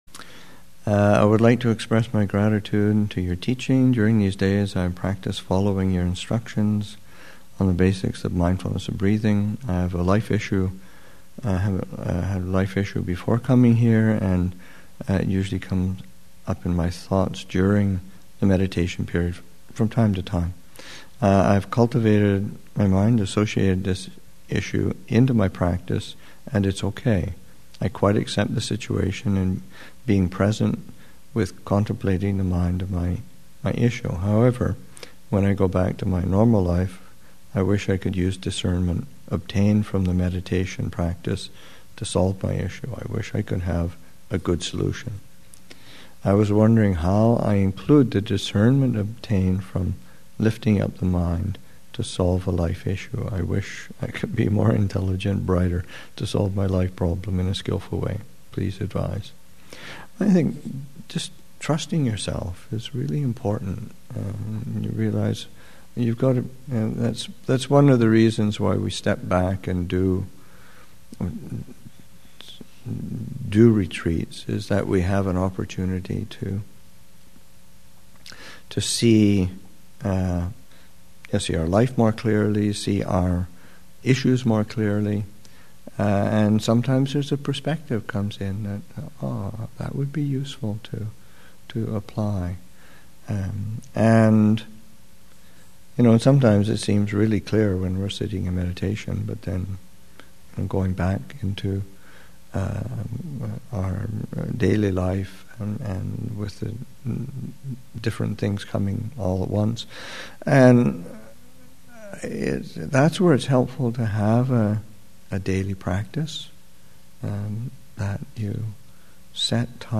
Thanksgiving Retreat 2016, Session 7 – Nov. 25, 2016